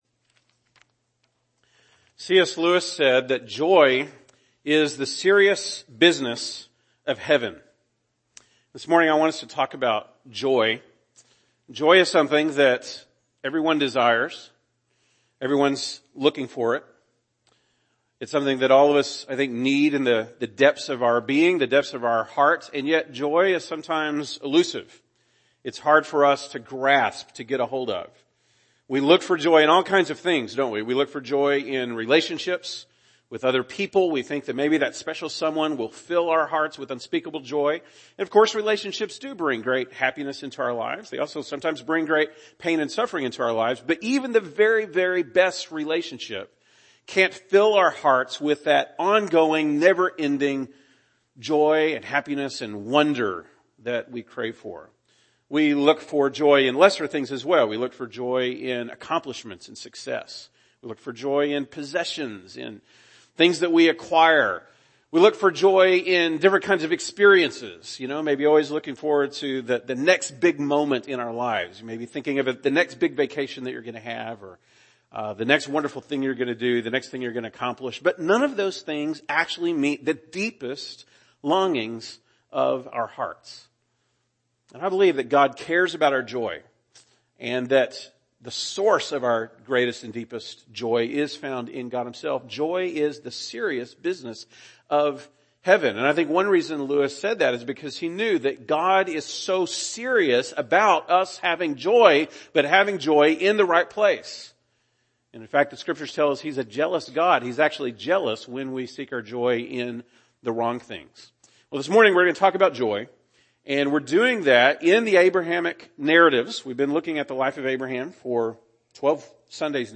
August 18, 2019 (Sunday Morning)